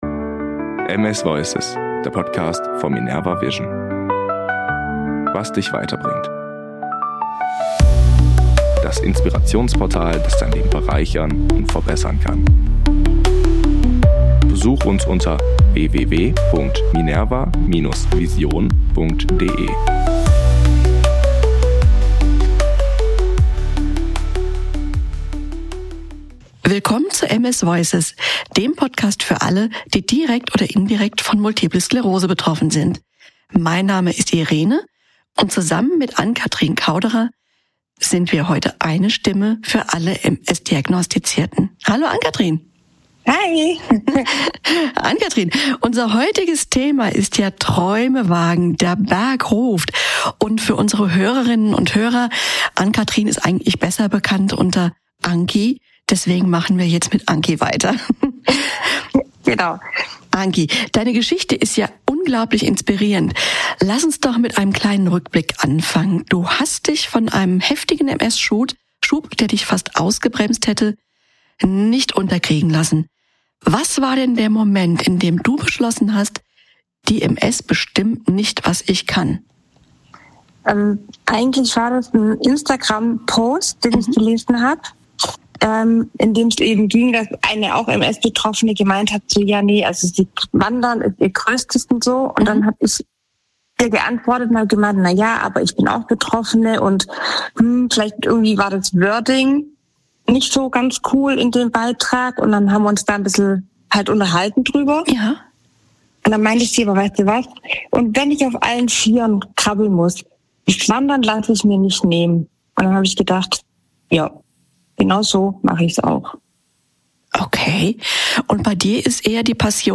Seid gespannt auf ein Gespräch voller Inspiration, Mut und dem unbändigen Willen, Träume zu wagen.